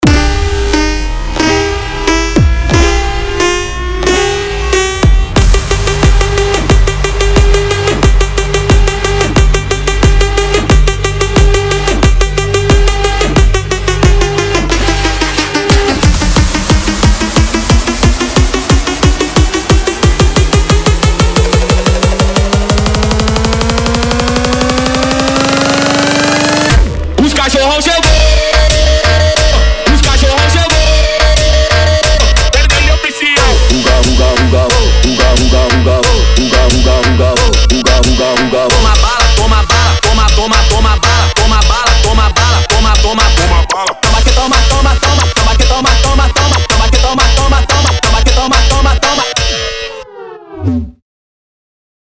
Tecno Melody 2023